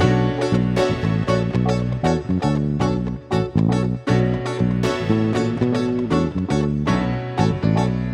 12 Backing PT3.wav